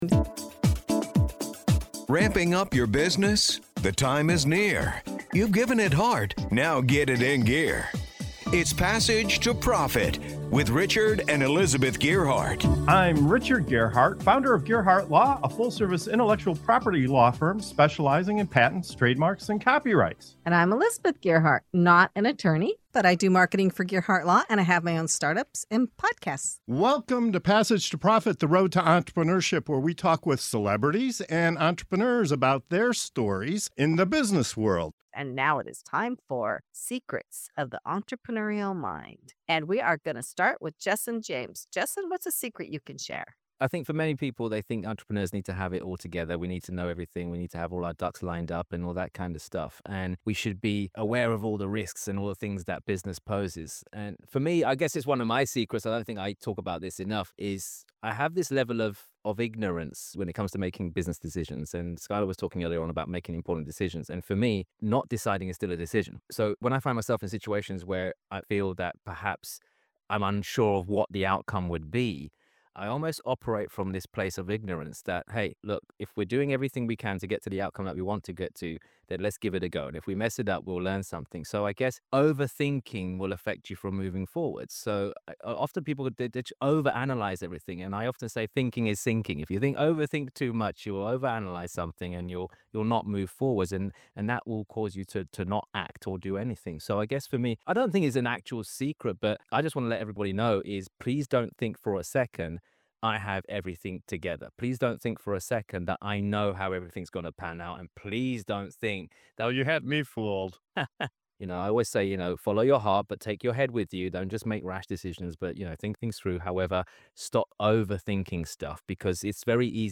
In this segment of of "Secrets of the Entrepreneurial Mind" on Passage to Profit Show, our guests reveal the unexpected truths behind success—why overthinking can sink you, how failure is your greatest teacher, and why smart decision-making is about more than just acting fast.